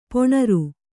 ♪ poṇaru